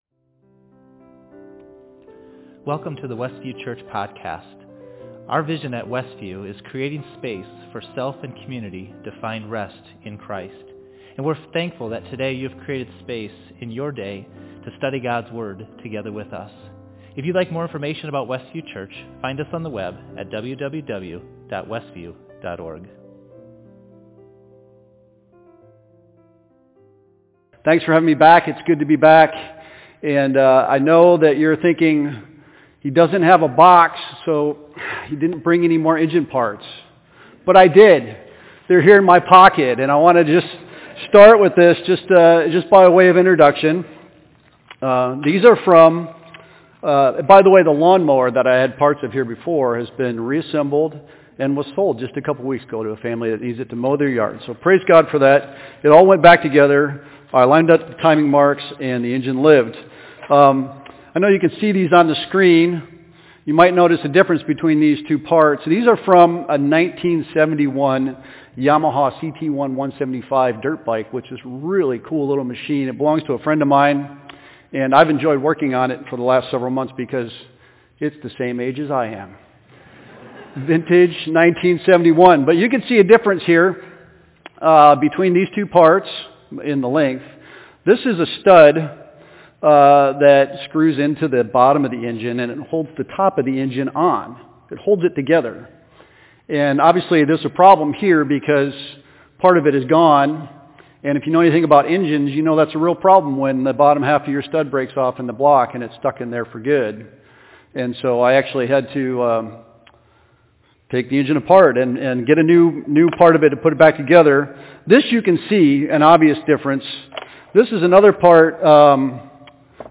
Facebook Livestream